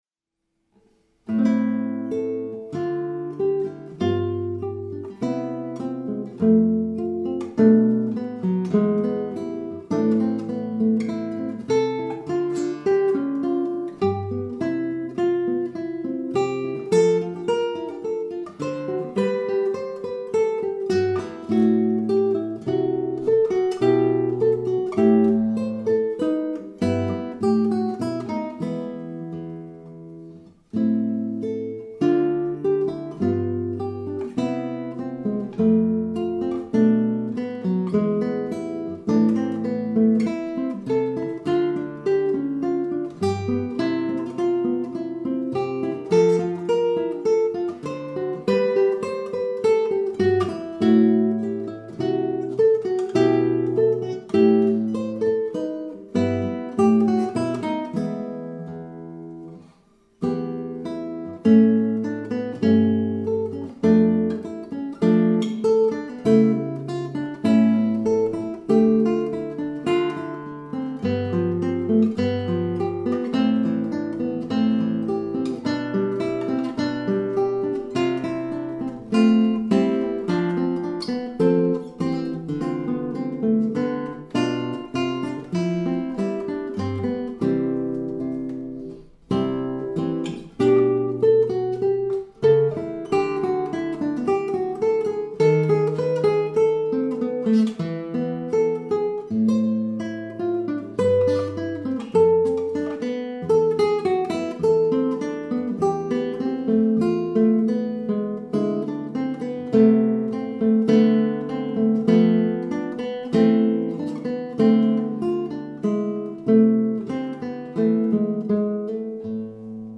[mp3] Bach Gavotte 1 de la Suite en la mineur - Guitare Classique